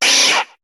Cri de Rattatac dans Pokémon HOME.